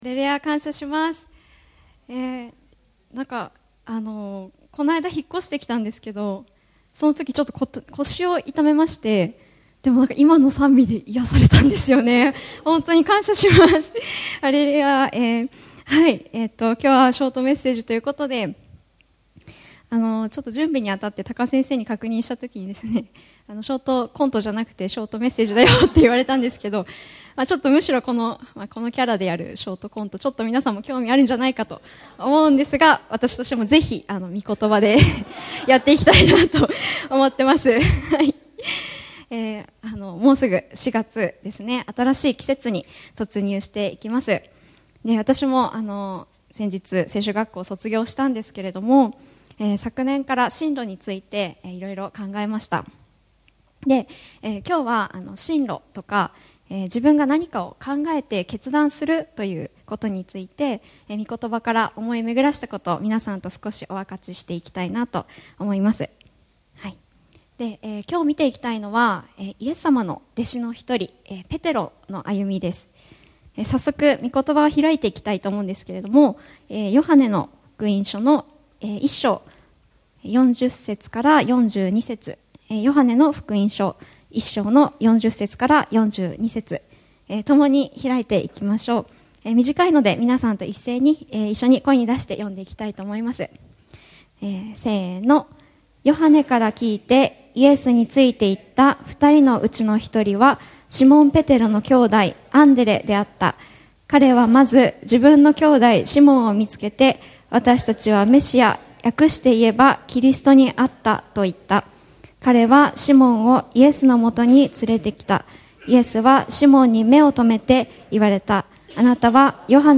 日曜礼拝